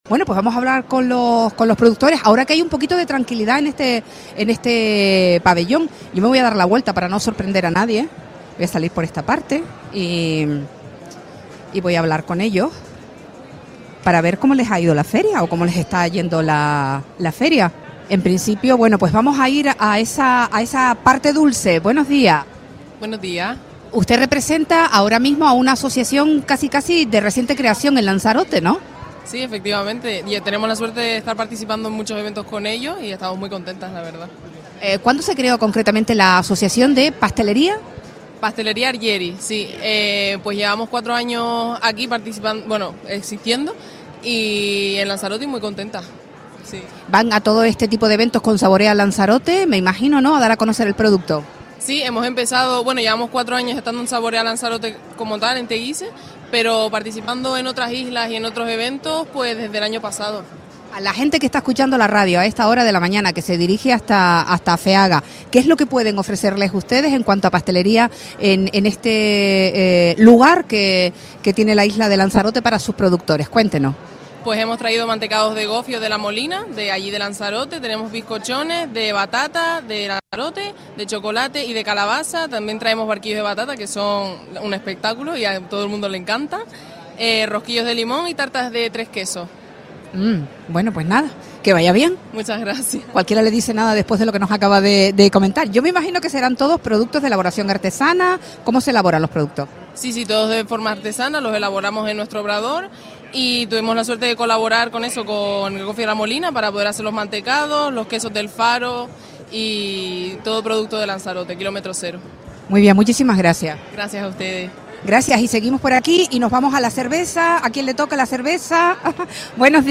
Hacemos un recorrido por Lanzarote en Feaga 2026 para conocer las impresiones de los productores asistentes a la feria Deja un comentario
Entrevistas